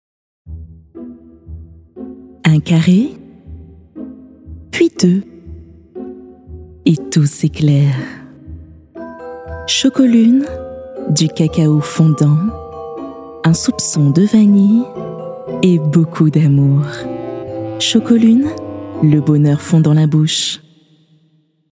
extrait livre audio
- Mezzo-soprano